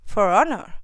summoner_ack7.wav